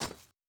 Impact on Snow.wav